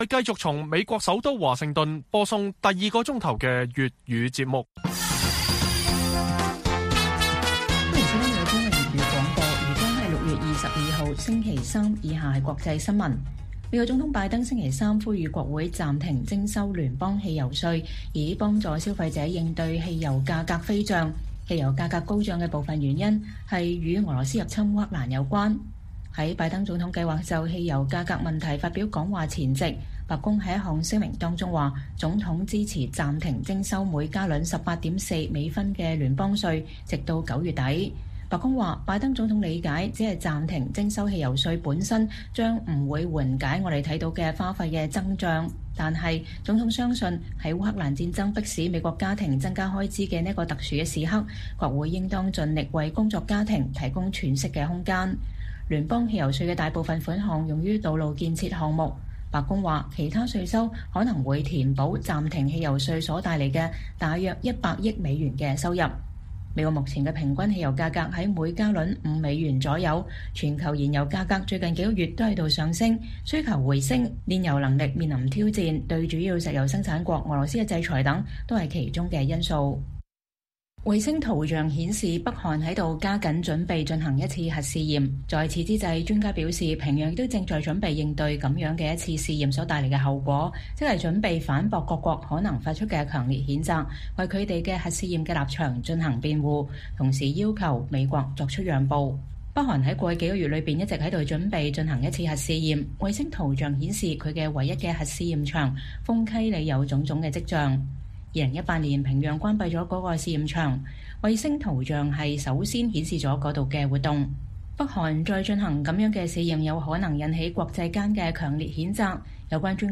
粵語新聞 晚上10-11點 : 國際人權組織：香港公民權利大跌 排名接近委內瑞拉及沙地阿拉伯